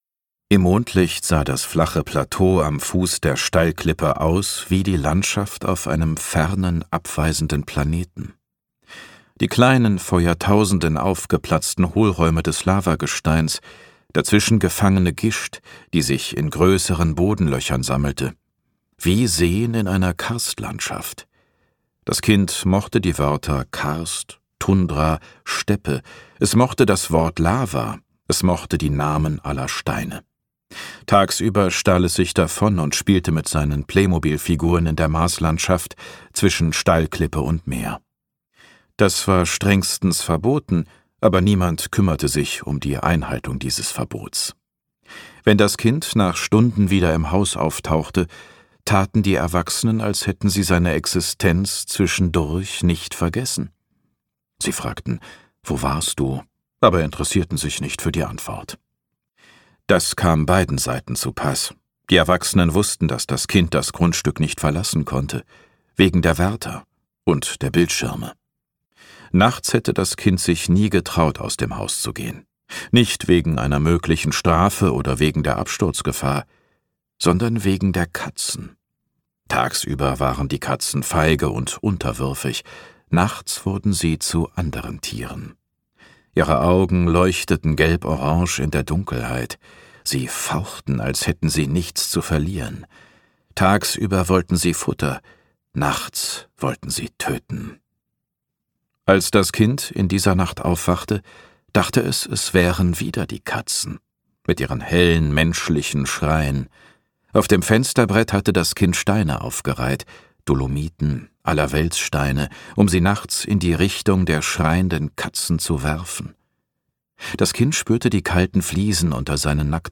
Gekürzt Autorisierte, d.h. von Autor:innen und / oder Verlagen freigegebene, bearbeitete Fassung.
Hörbuchcover von Meeresdunkel: Zwei Familien. Ein Ferienhaus. Ein Sommer, der tödlich endet.